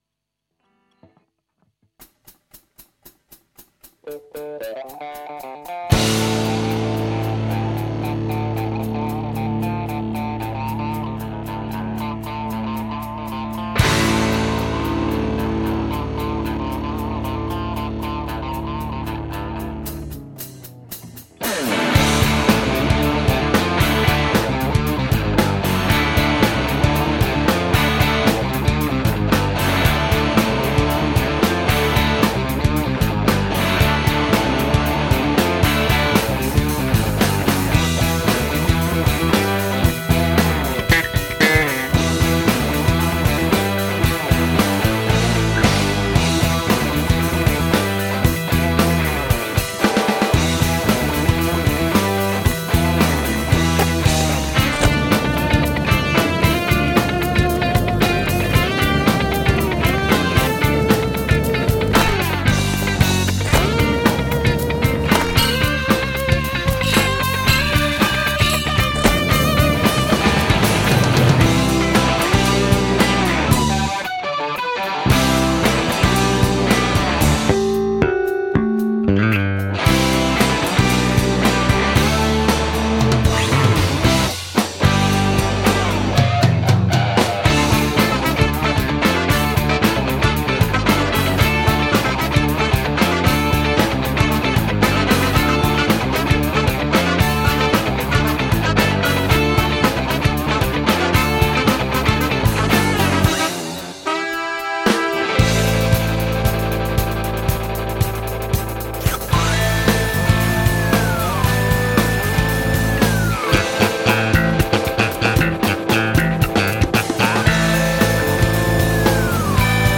kitara, vokal
bobni, tolkala, vokal
sax, klaviature, vokal
kitara, klavir, vokal
zabaven, tehnično dovršen in nadžanrski bend